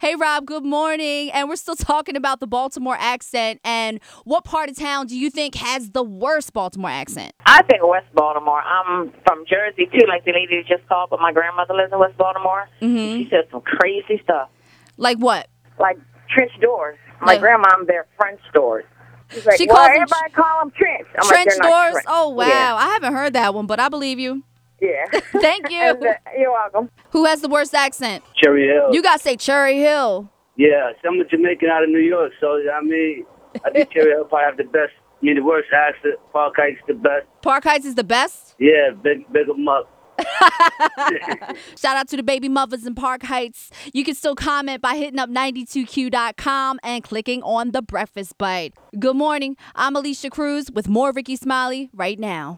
CLICK TO HEAR WHAT SOME CALLERS HAD TO SAY SEE ALSO Diva’s Daily Dirt: Rihanna Is Now Officially A Billionaire ✕
breakfast-bite-accents1.wav